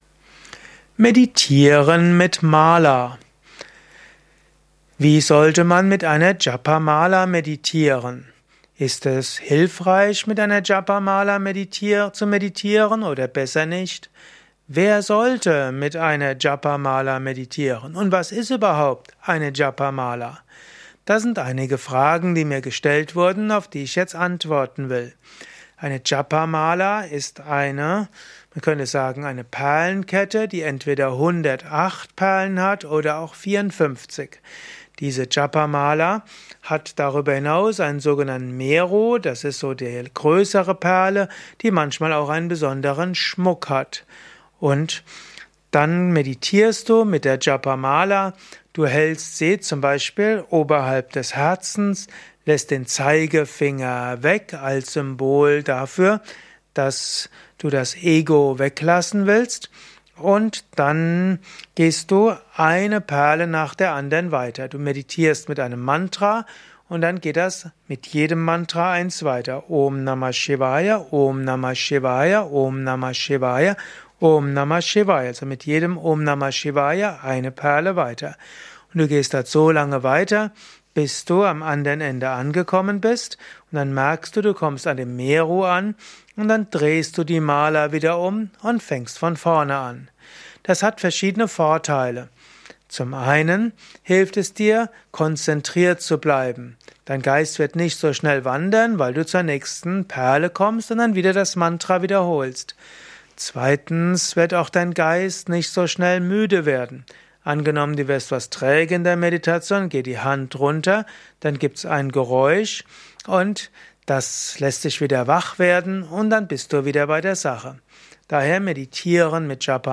Dieser Kurzvortrag gehört zum Meditation Video Podcast.